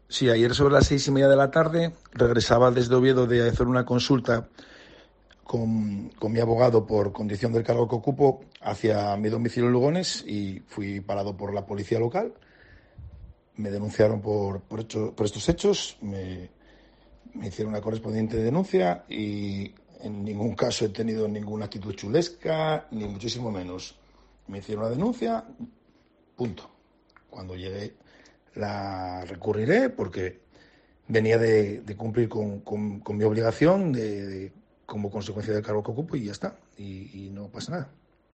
Explicación del alcalde de Siero tras ser denunciado por la Policía Local de Oviedo